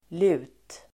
Ladda ner uttalet
lut substantiv, Uttal: [lu:t] Synonymer: natriumhydroxidIdiom: (ha el. ligga) på lut ("i beredskap") ((have or be) up one's sleeve ("ready"))